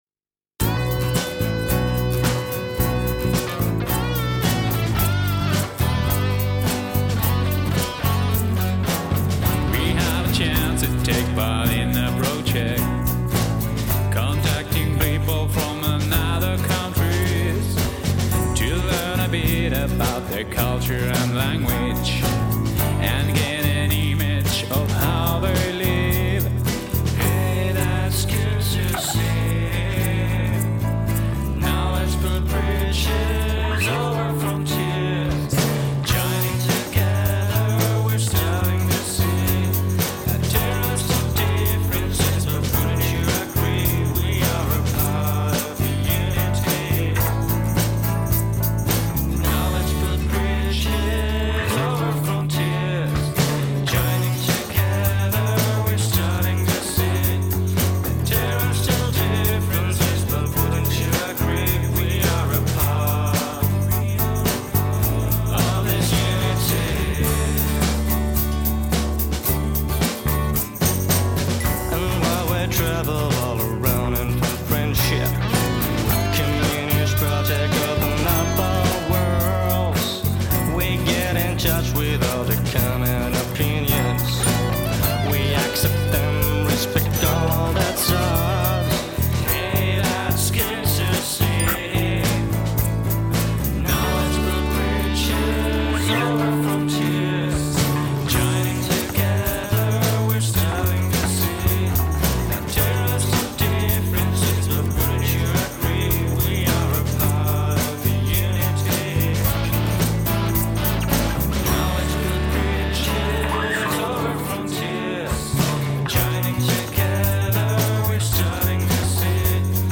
Finnish pupils
on the keyboards